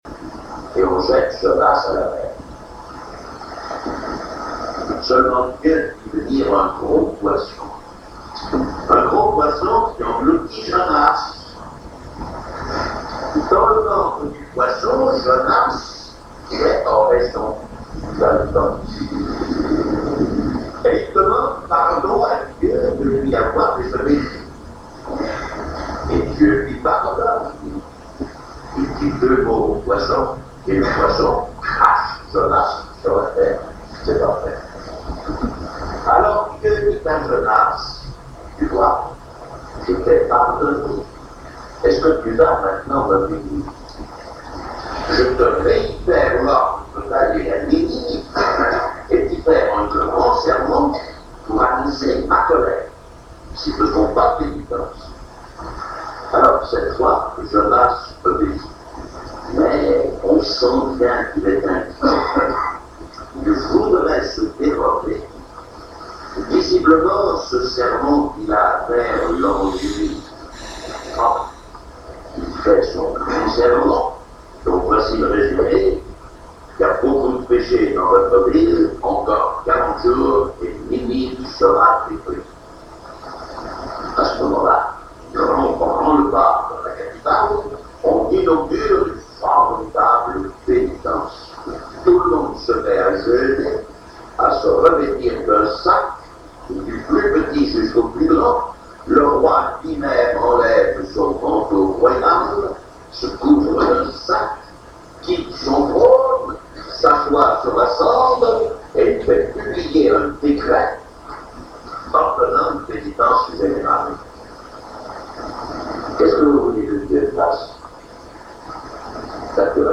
Le Père François Varillon raconte l’histoire de Jonas